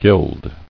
[guild]